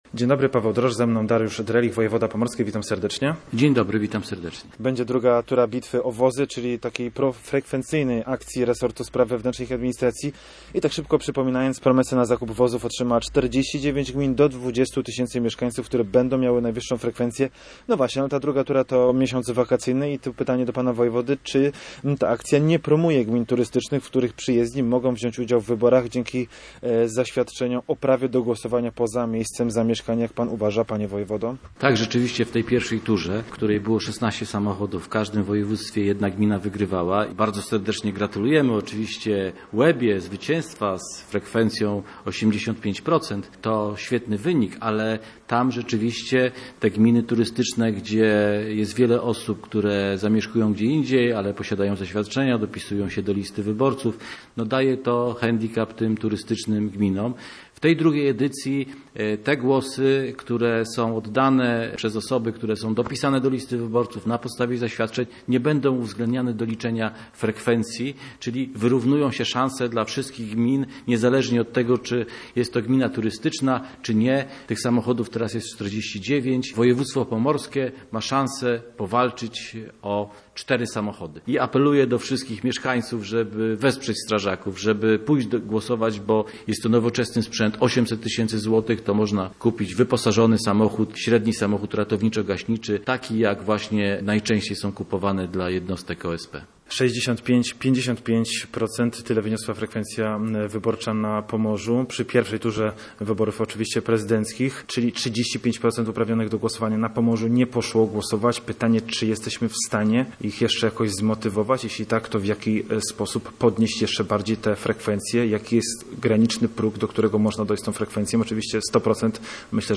Dariusz Drelich, gość programu miejskiego Radia Gdańsk w Słupsku, mówił o kolejnej szansie pomorskich gmin na wygranie wozu strażackiego. Poruszył również temat procedur bezpieczeństwa wprowadzonych po nawałnicy z 2017 roku i terminach budowy trasy ekspresowej S6 w regionie słupskim.